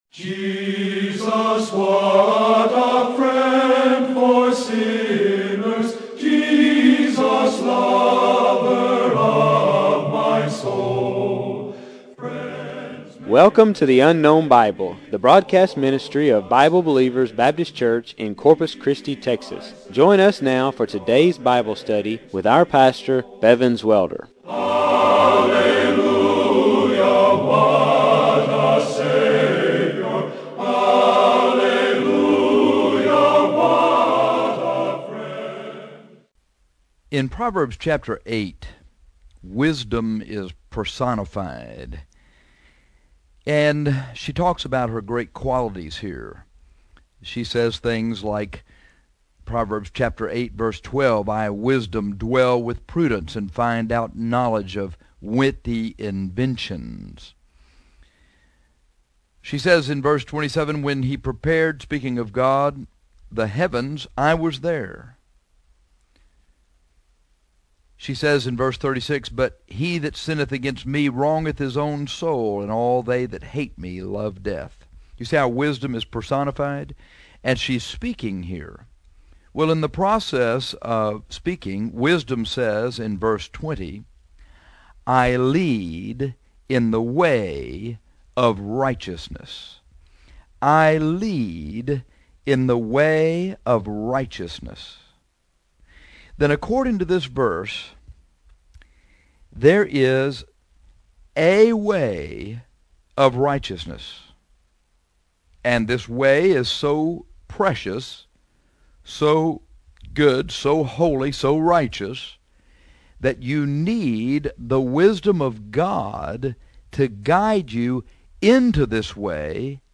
Through preaching about the way of righteousness, we hope that you find this way so desirable that you will want to get on it and stay on it. We’ll preach about the defining characteristics of this way of righteousness.